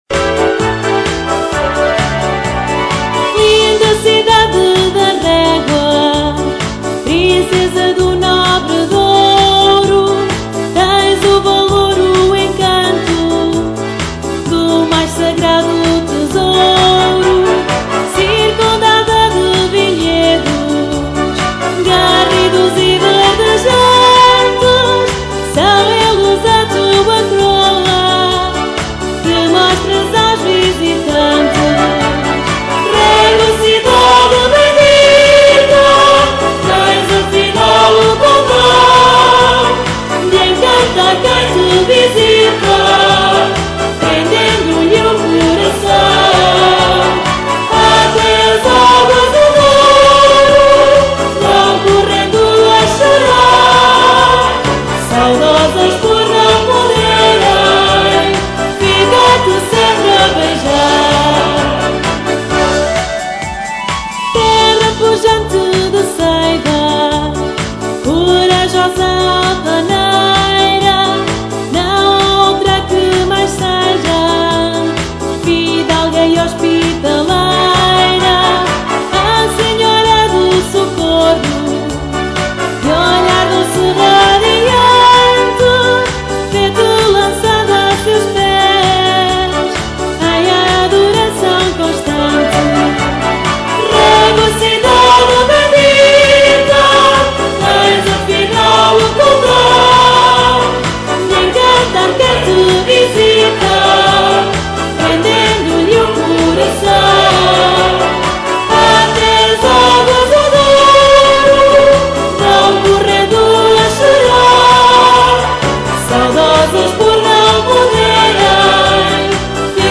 MARCHA RÉGUA, CIDADE GRACIOSA (voz)